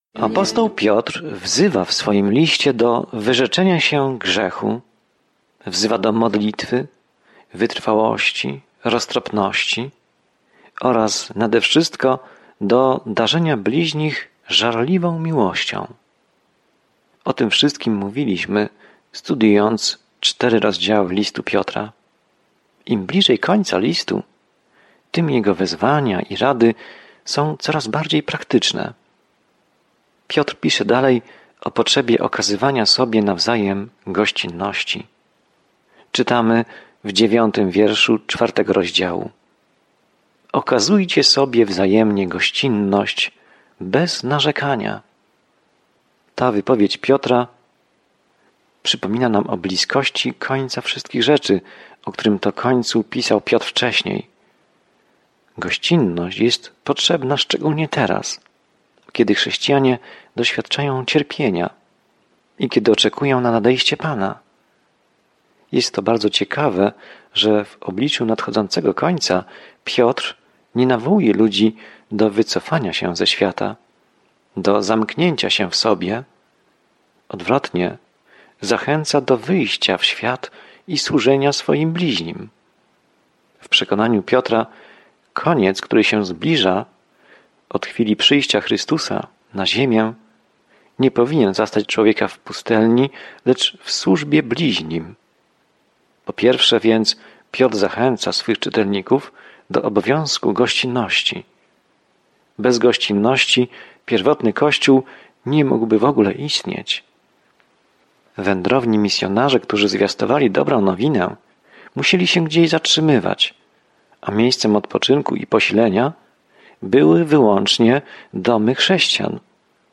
Pismo Święte 1 Piotra 4:9-16 Dzień 13 Rozpocznij ten plan Dzień 15 O tym planie Jeśli cierpisz dla Jezusa, ten pierwszy list Piotra zachęca cię, abyś podążał śladami Jezusa, który pierwszy cierpiał za nas. Codziennie podróżuj przez I List Piotra, słuchając studium audio i czytając wybrane wersety ze słowa Bożego.